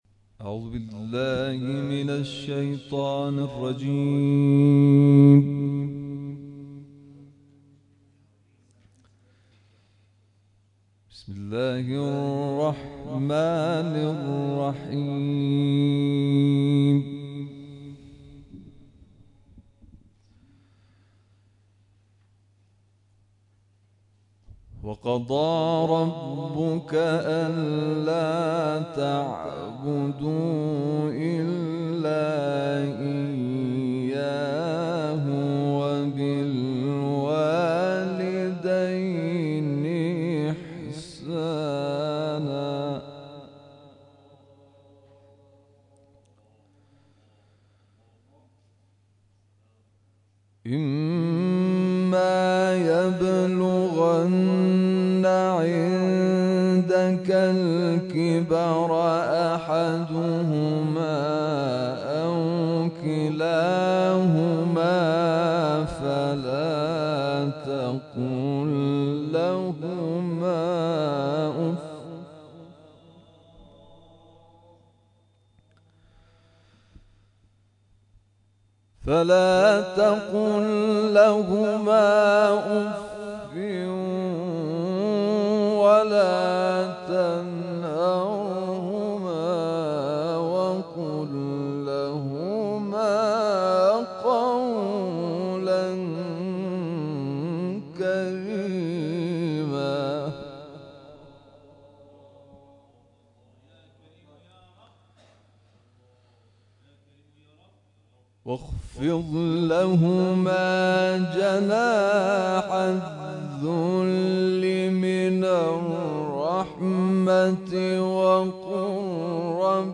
جلسه قرآن گرم و صمیمی در دمای «منفی 7» + صوت و عکس